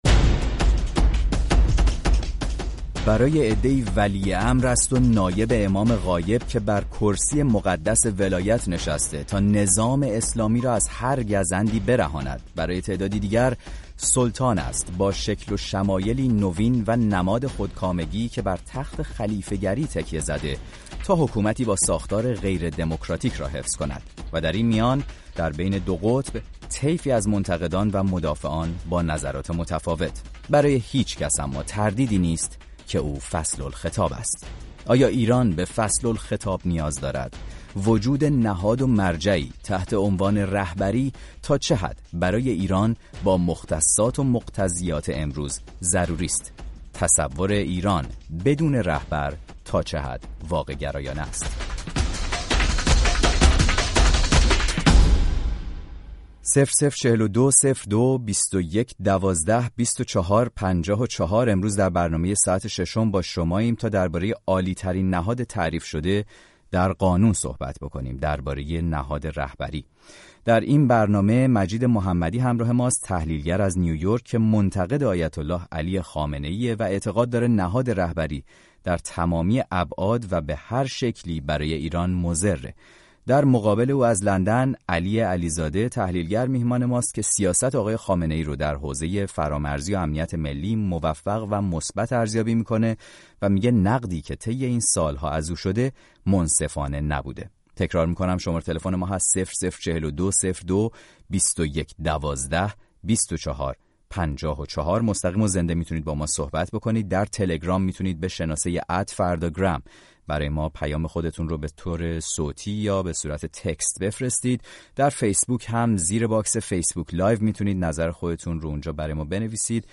در این برنامه مناظره یک منتقد و یک مدافع نهاد رهبری را در کنار نظرات مخاطبان رادیو فردا می شنوید.